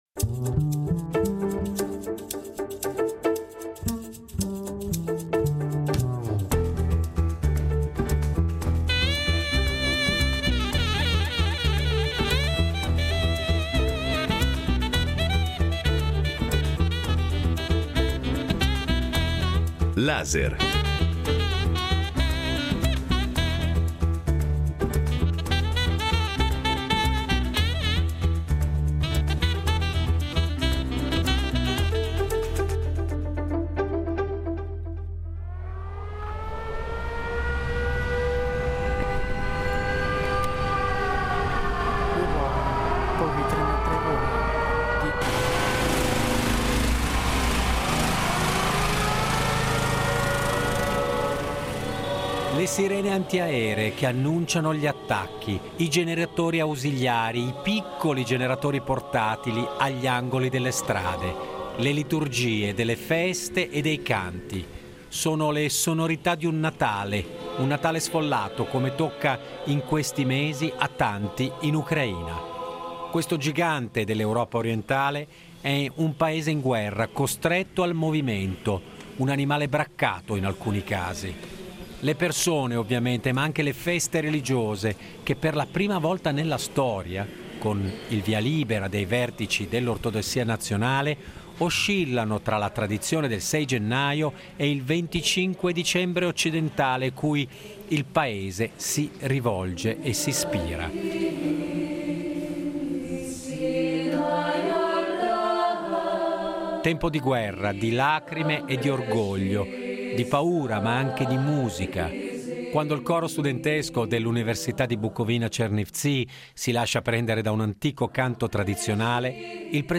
Documentario: Ucraina, l’anno zero sognando la libertà